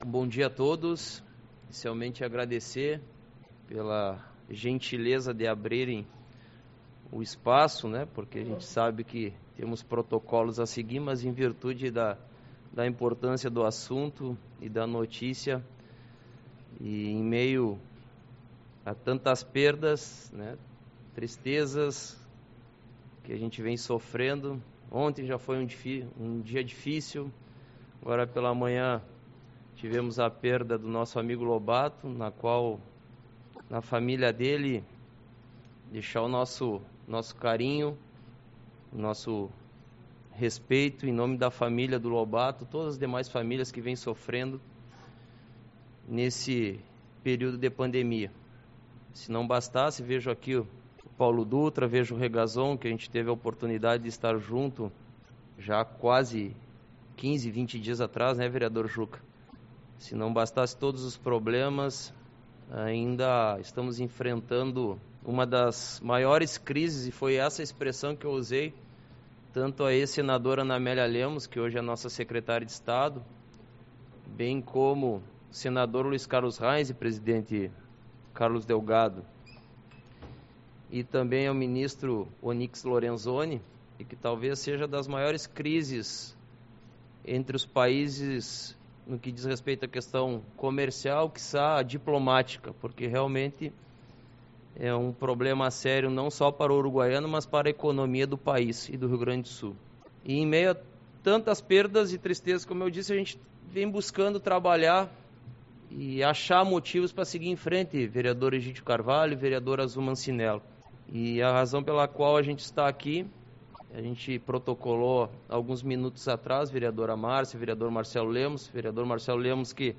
Reunião Ordinária